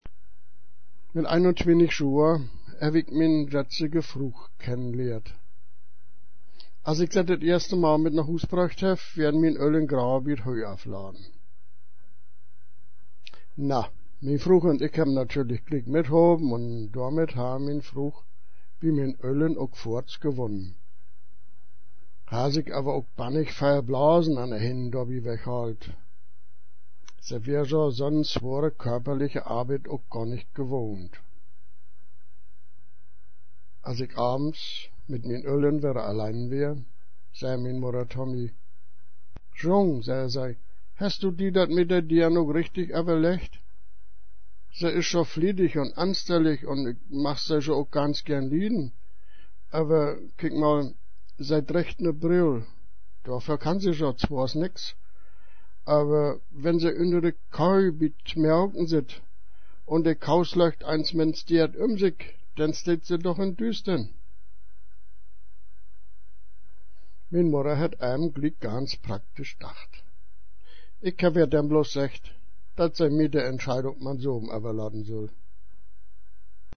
Hörbuch im breiten ländlichen westmecklenburger Plattdeutsch gesprochen;